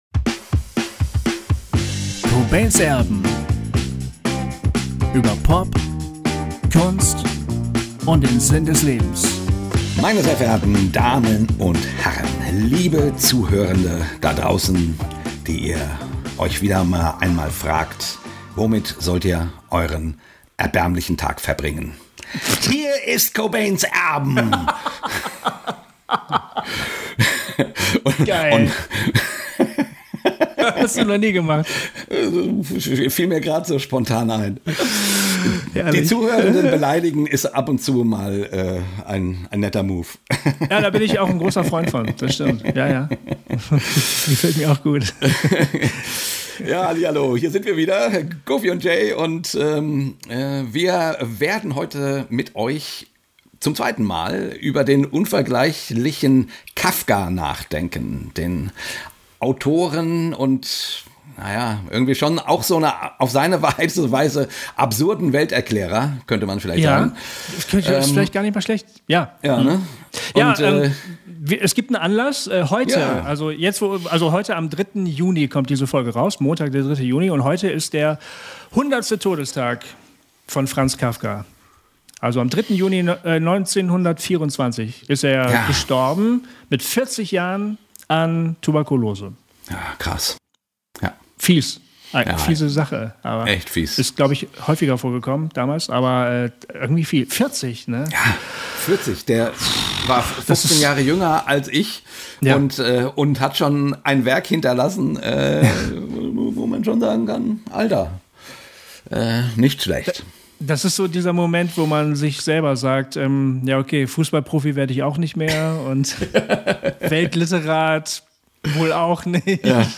Darüber reden wir: über das Lachen als Form des Widerstandes, über Kafkas Schreiben gegen die Mächte und die Ausweglosigkeit und auch darüber, was es mit Sex zu tun hat. Ein Talk, bei dem Ihr noch einiges Neues über diesen großartigen Autoren erfahren werdet.